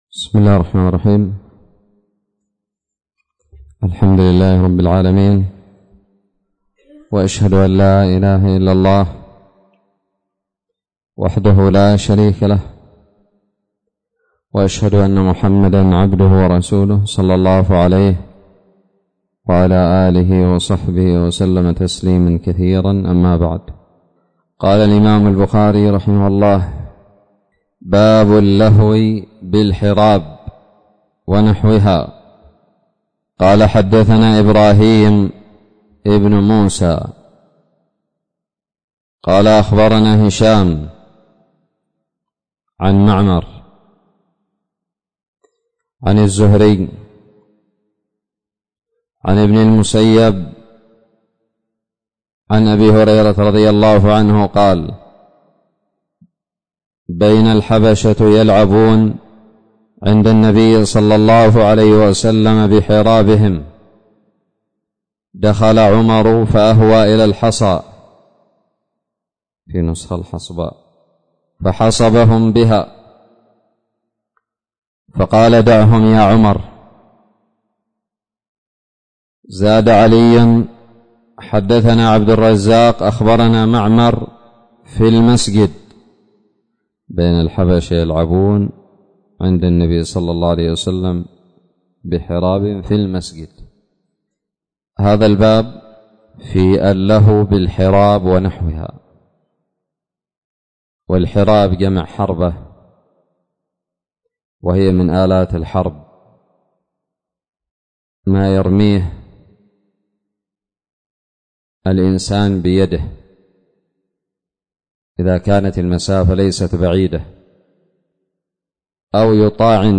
ألقيت بدار الحديث السلفية للعلوم الشرعية بالضالع